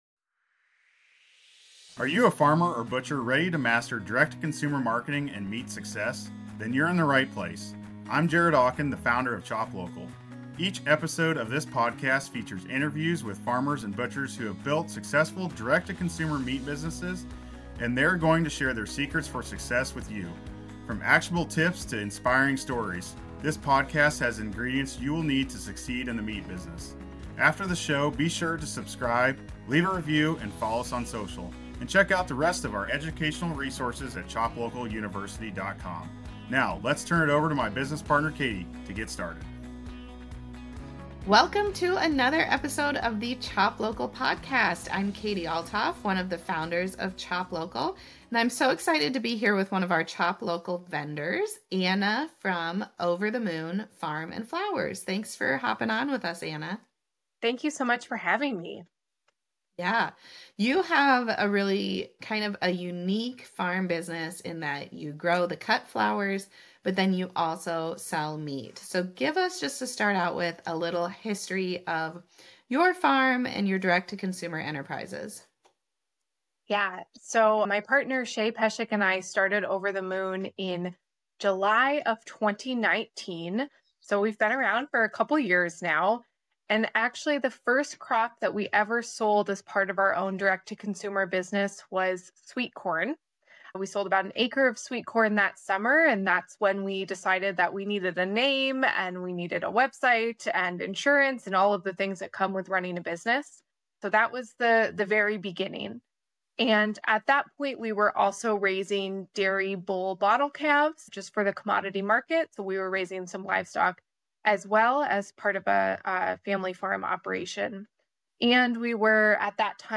The conversation explores the challenges and successes of navigating different markets, understanding customer demographics, and the importance of diversifying protein offerings.